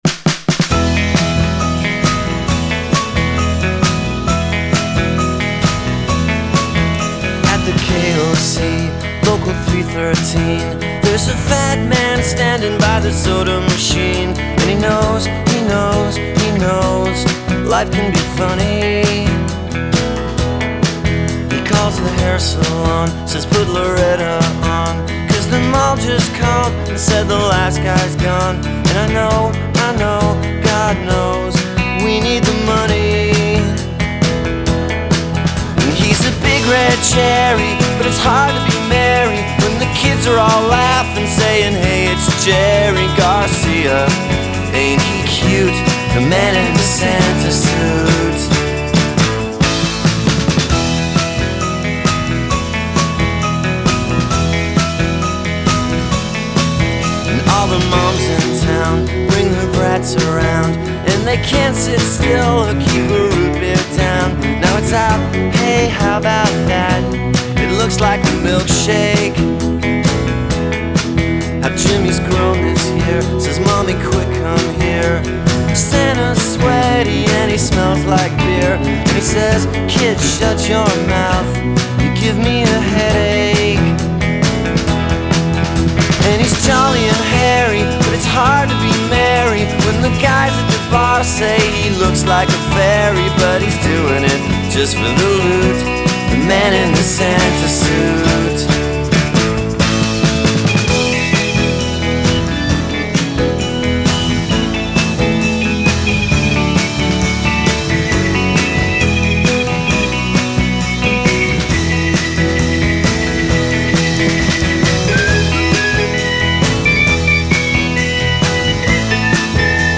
The song has great hooks